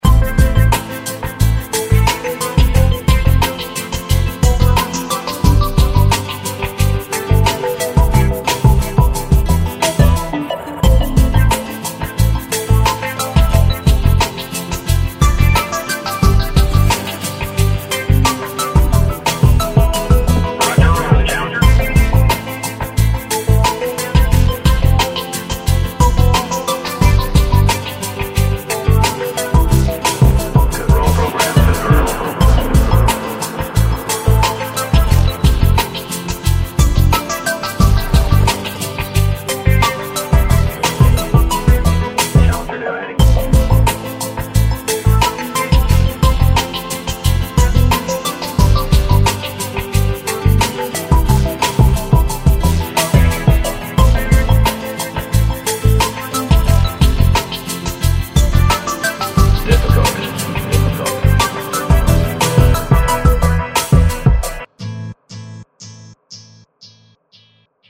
Завораживающие
спокойная мелодия